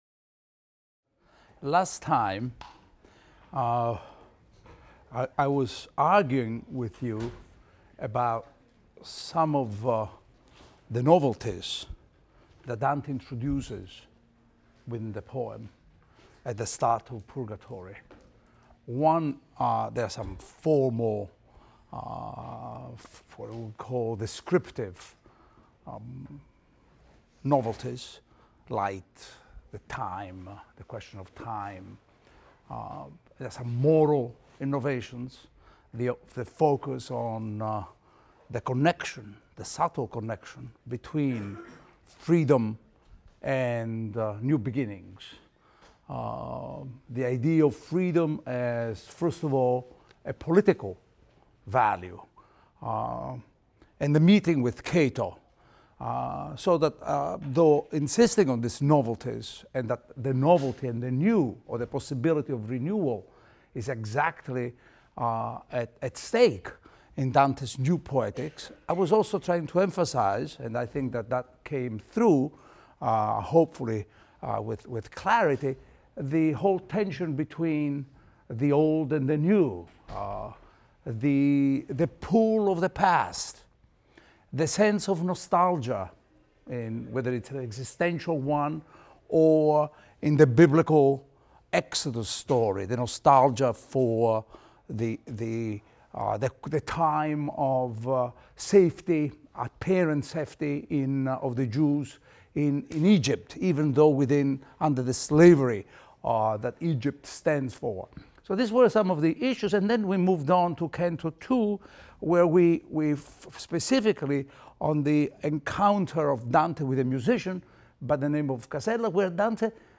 ITAL 310 - Lecture 11 - Purgatory V, VI, IX, X | Open Yale Courses